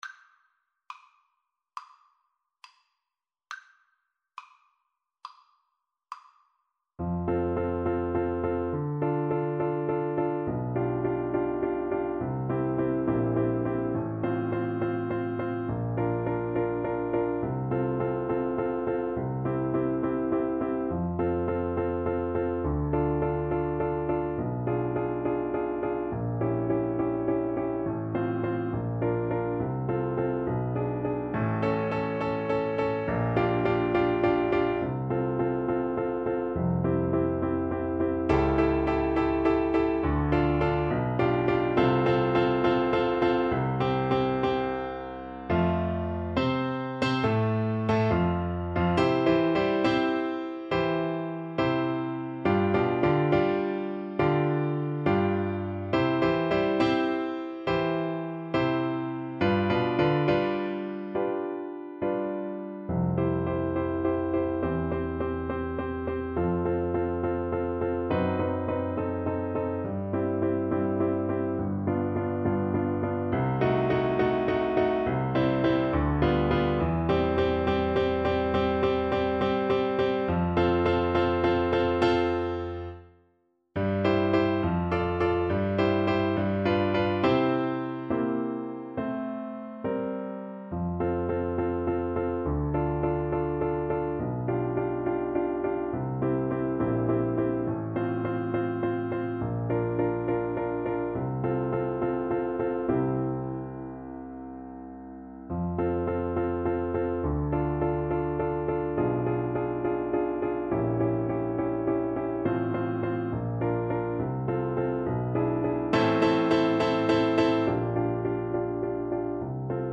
Play (or use space bar on your keyboard) Pause Music Playalong - Piano Accompaniment Playalong Band Accompaniment not yet available reset tempo print settings full screen
F major (Sounding Pitch) G major (Trumpet in Bb) (View more F major Music for Trumpet )
4/4 (View more 4/4 Music)
Classical (View more Classical Trumpet Music)